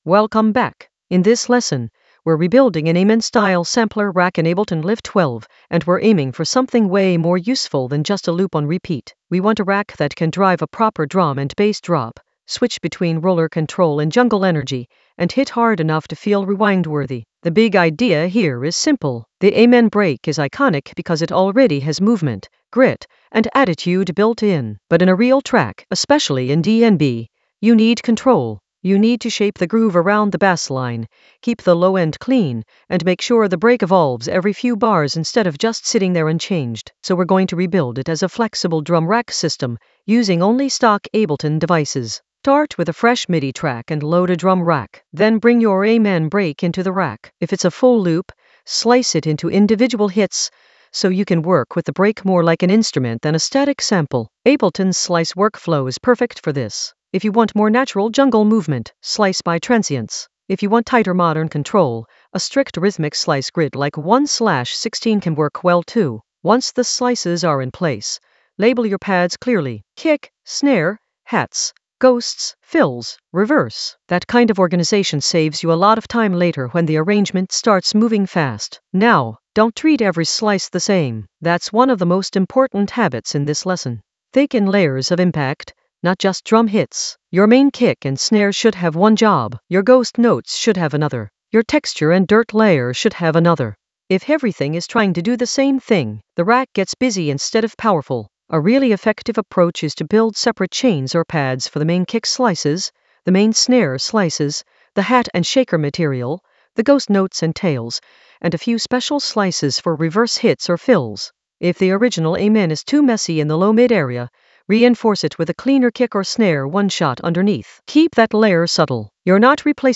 An AI-generated intermediate Ableton lesson focused on Rebuild an Amen-style sampler rack for rewind-worthy drops in Ableton Live 12 in the Mixing area of drum and bass production.
Narrated lesson audio
The voice track includes the tutorial plus extra teacher commentary.